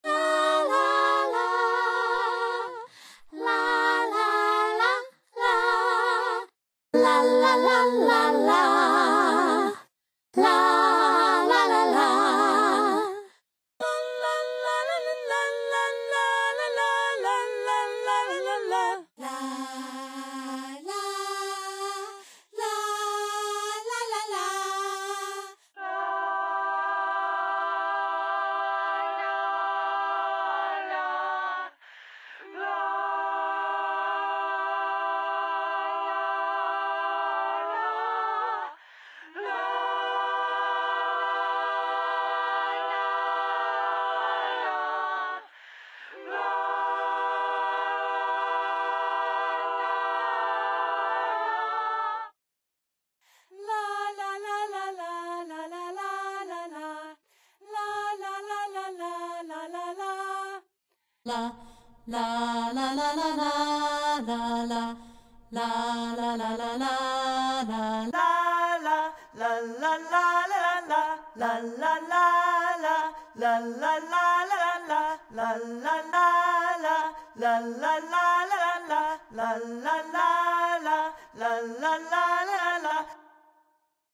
Звук пения монстра